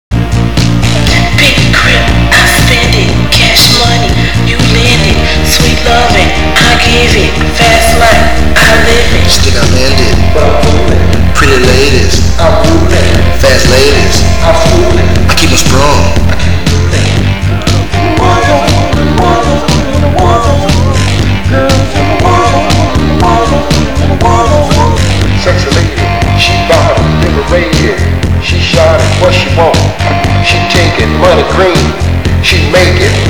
HIPHOP!!